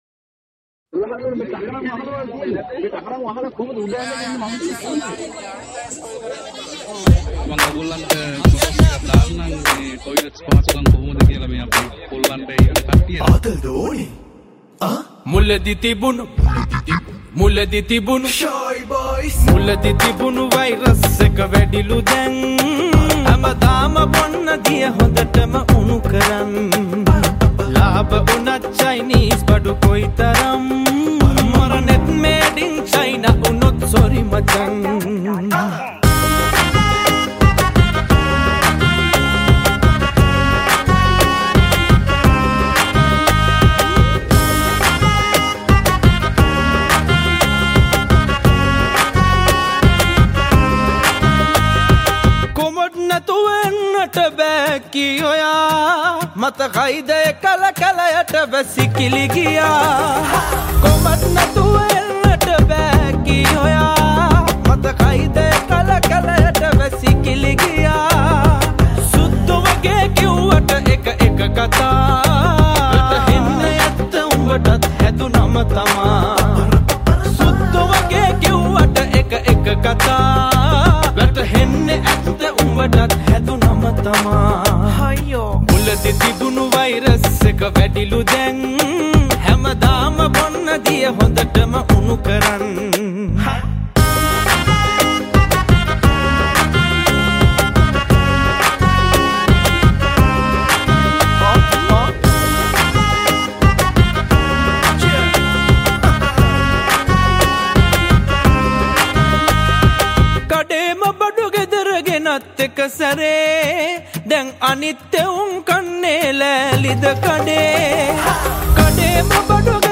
funny song, parody song, sinhala sindu, new song sinhala